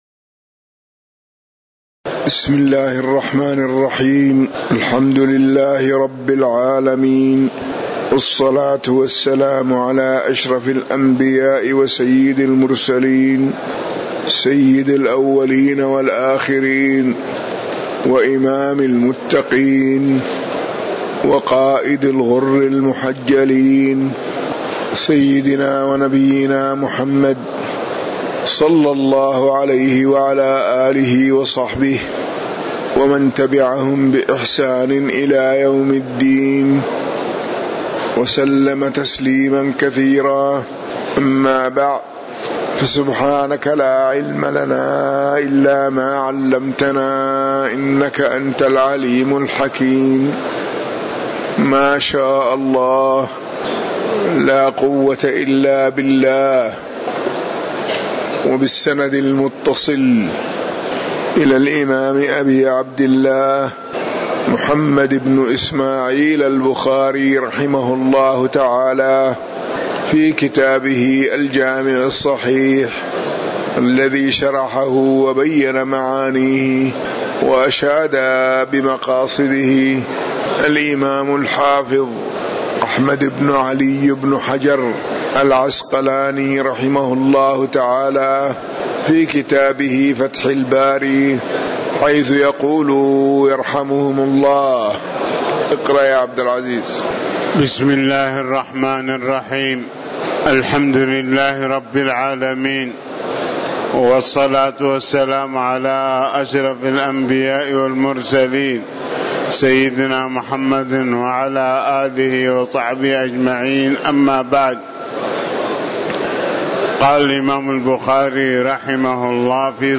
تاريخ النشر ١٢ شعبان ١٤٤٠ هـ المكان: المسجد النبوي الشيخ